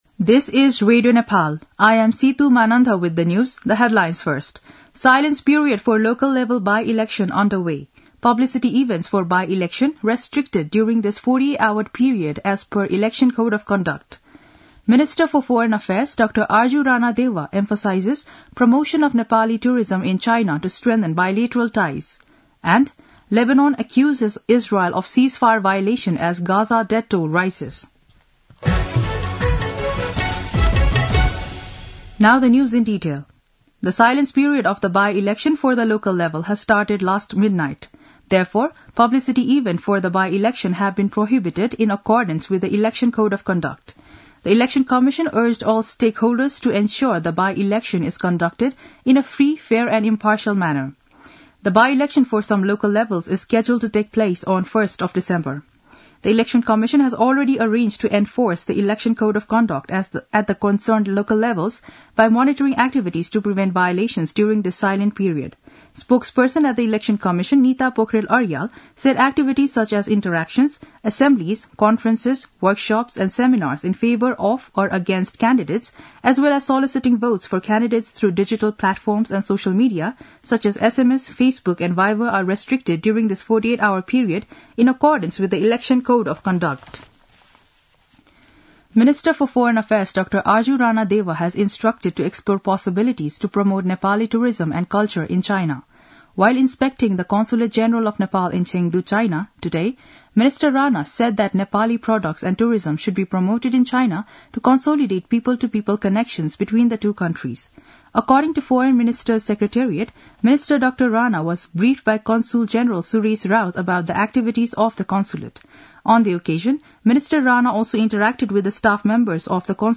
दिउँसो २ बजेको अङ्ग्रेजी समाचार : १५ मंसिर , २०८१
2-pm-english-news-1-12.mp3